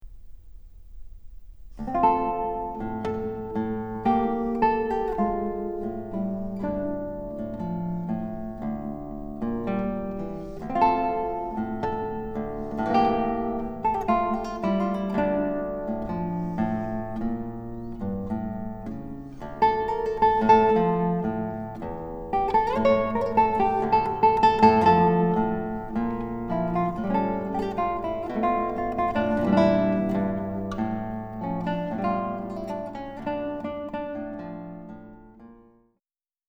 für Gitarre
guitar